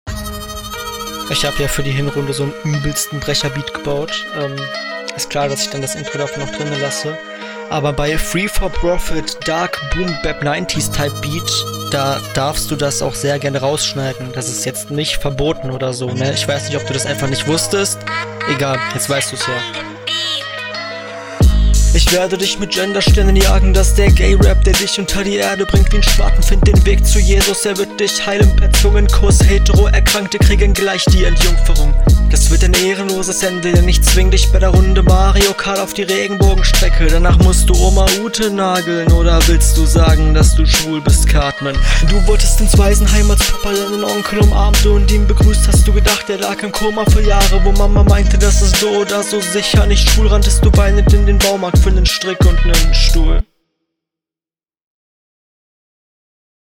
Was solln die Heulerei am Anfang?